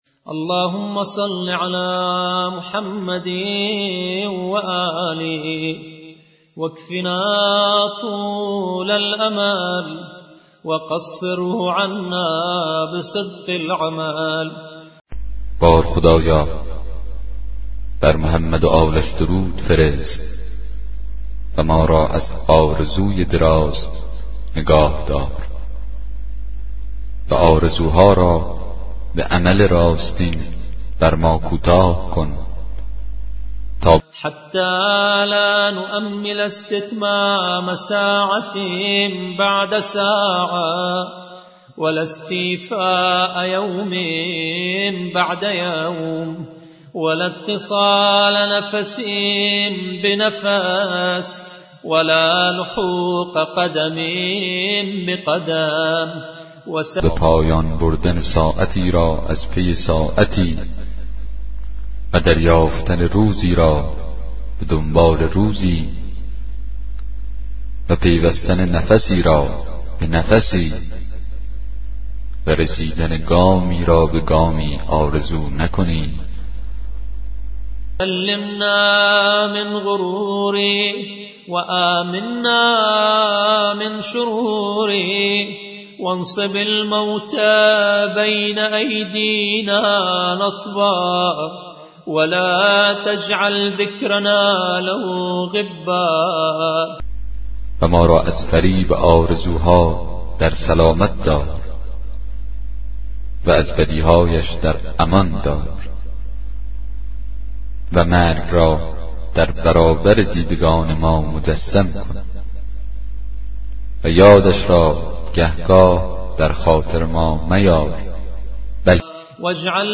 کتاب صوتی دعای 40 صحیفه سجادیه